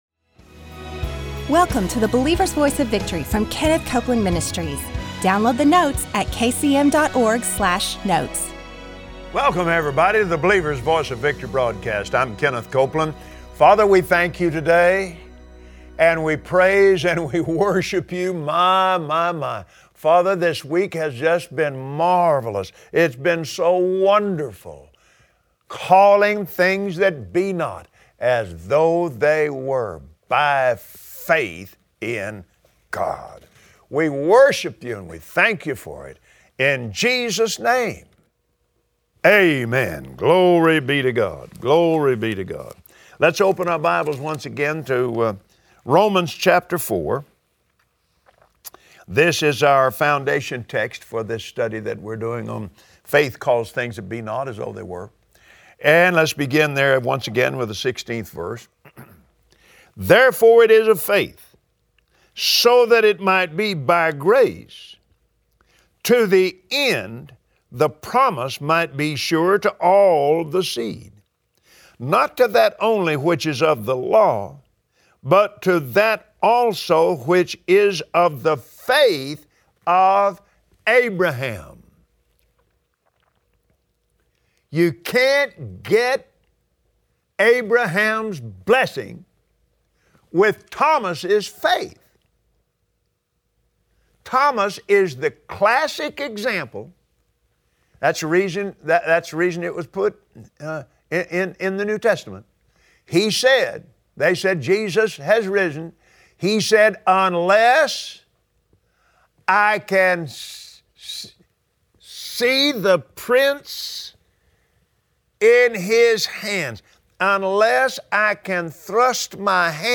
Believers Voice of Victory Audio Broadcast for Friday 08/12/2016 Faith calls those things that be not as though they were. Today, on the Believer’s Voice of Victory, learn how faith operates as Kenneth Copeland shows us the process: Faith says it, does it, and sees it come to pass.